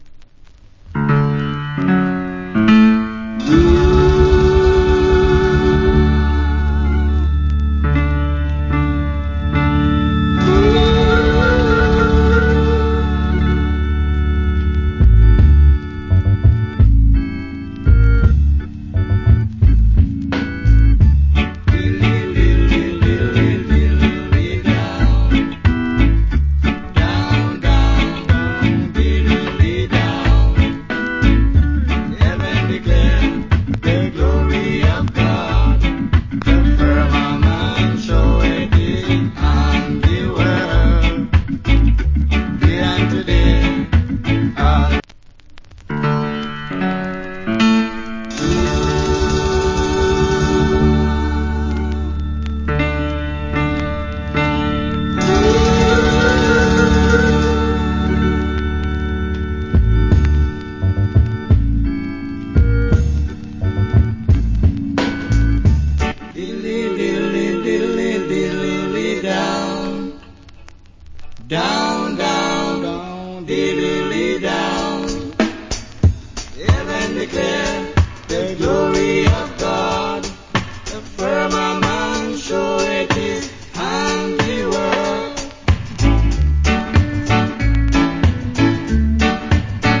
Nice Reggae Vical.